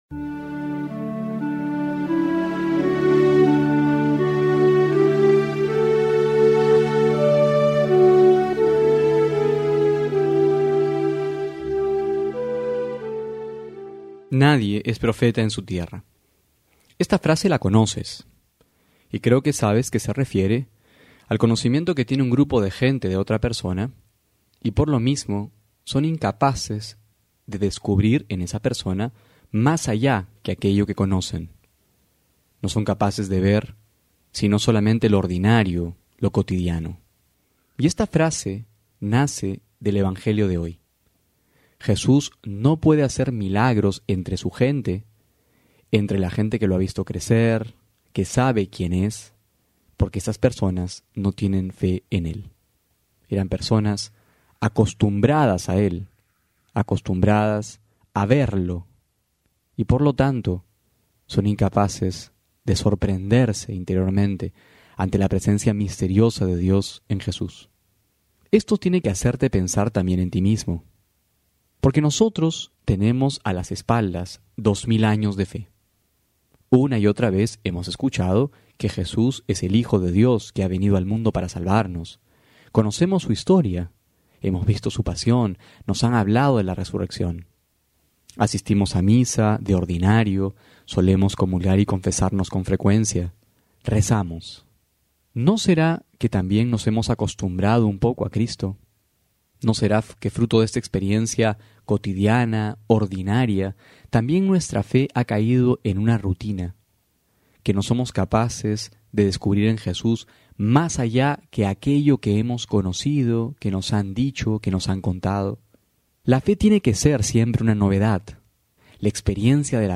febrero01-12homilia.mp3